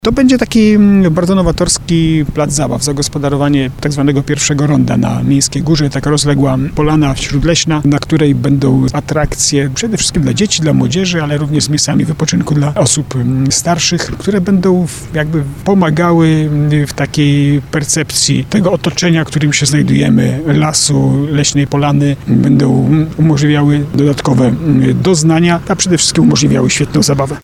O przeznaczeniu orbisfery mówi burmistrz Starego Sącza, Jacek Lelek.